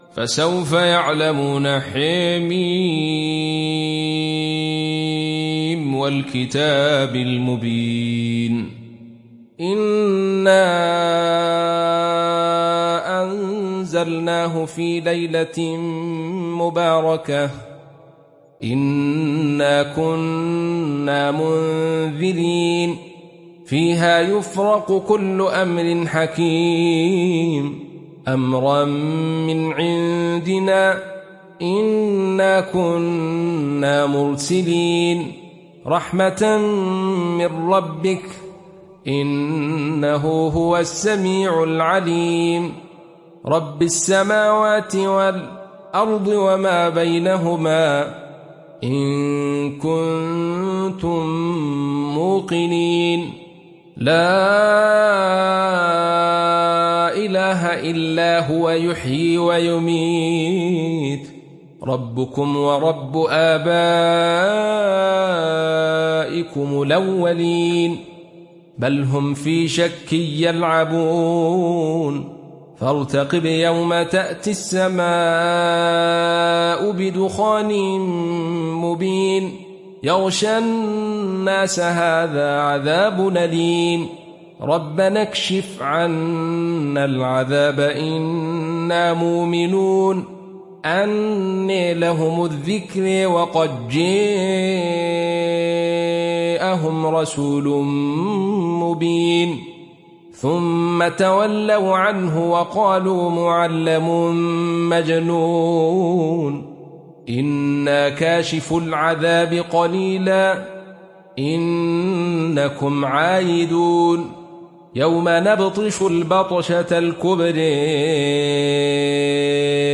Sourate Ad Dukhan Télécharger mp3 Abdul Rashid Sufi Riwayat Khalaf an Hamza, Téléchargez le Coran et écoutez les liens directs complets mp3